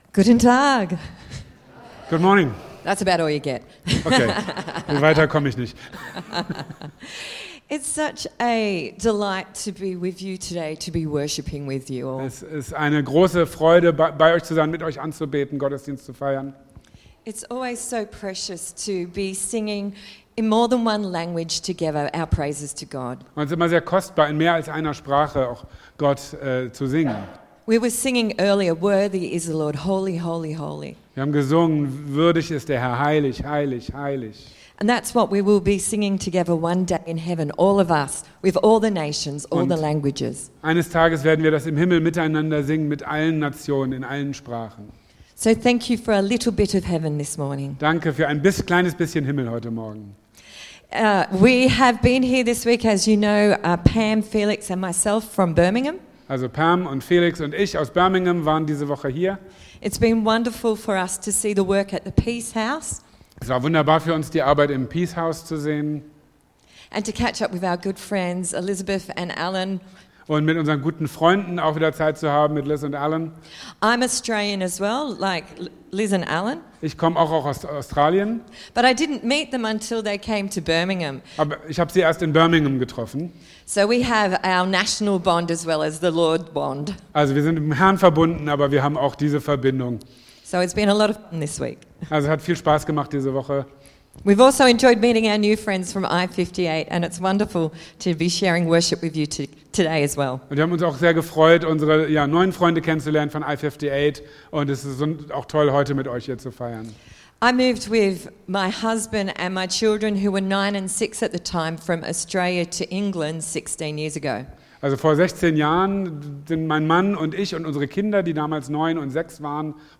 Predigt auf englisch mit deutscher Übersetzung.